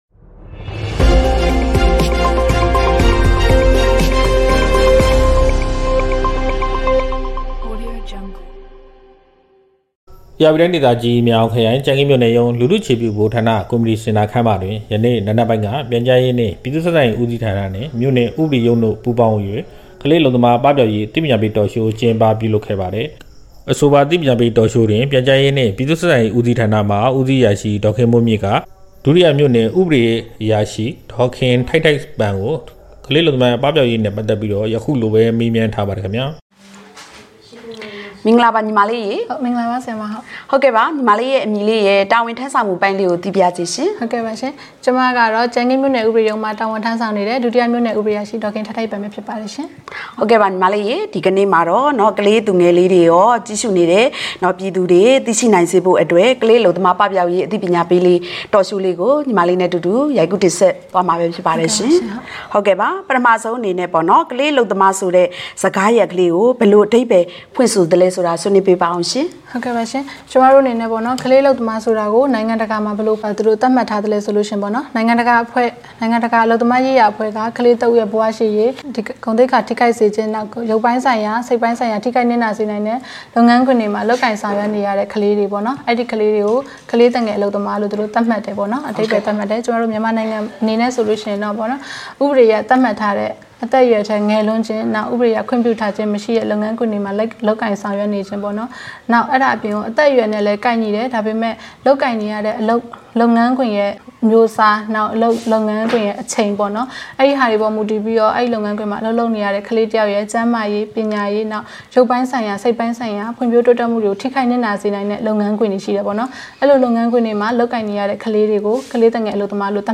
လူထုအ‌ခြေပြုဗဟိုဌာနတွင် ကလေးအလုပ်သမားပပျောက်ရေးအသိပညာပေး Talk Show ရိုက်ကူးထုတ်လွှင့်